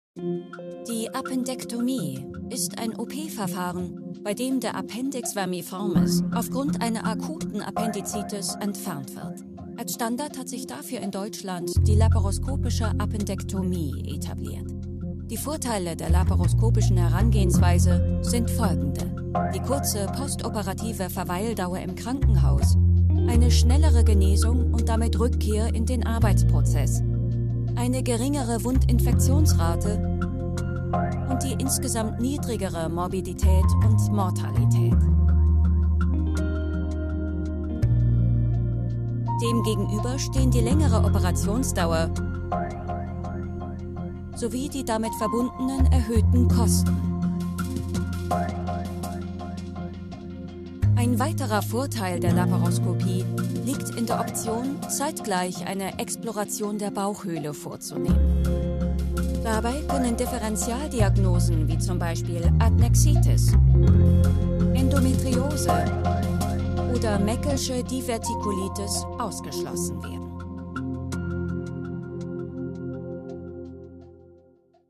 Medical Narration
My delivery is natural, engaging, and precise — ideal for brands that value credibility, emotion, and clarity.
🎧 Voice style: Warm · Modern · Confident · Engaging · Trustworthy · Natural